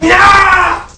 Worms speechbanks
Nooo.wav